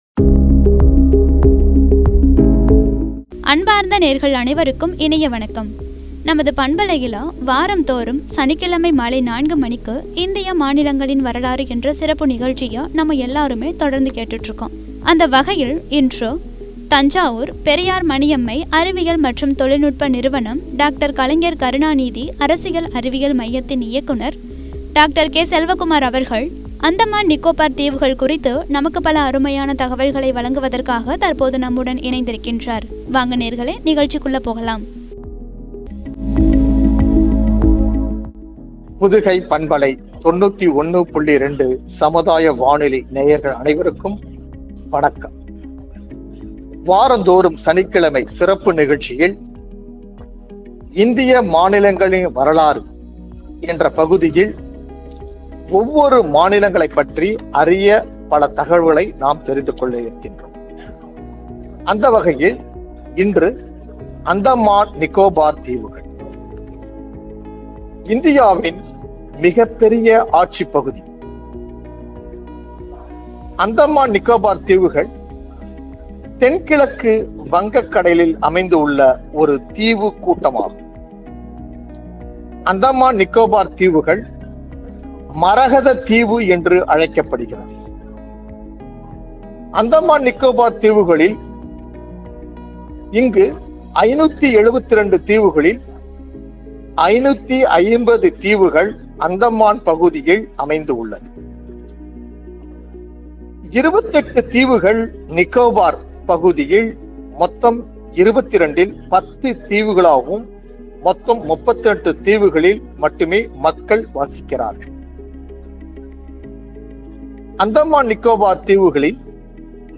வழங்கிய உரை.